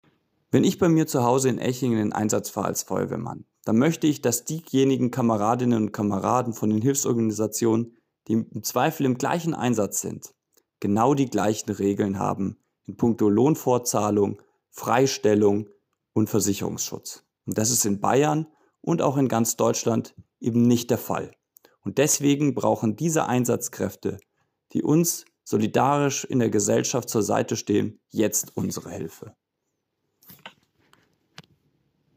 Leon_Eckert_MdB_O-Ton.m4a